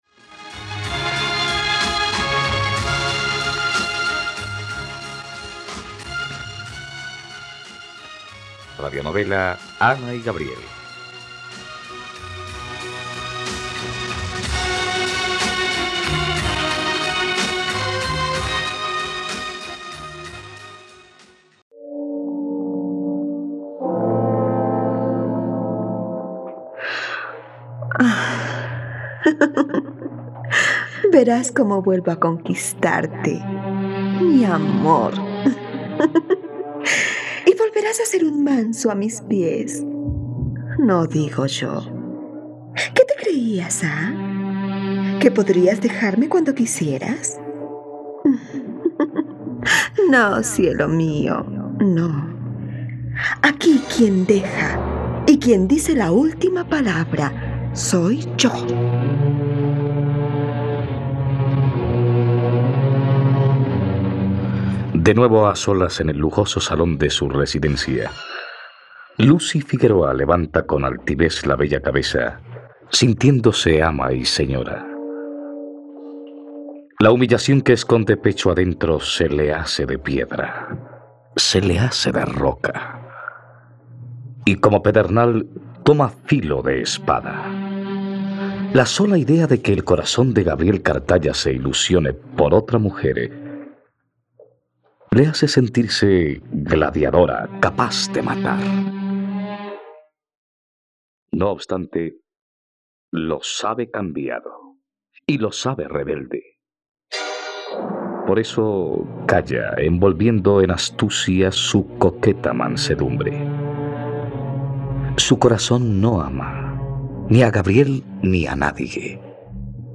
..Radionovela. Escucha ahora el capítulo 43 de la historia de amor de Ana y Gabriel en la plataforma de streaming de los colombianos: RTVCPlay.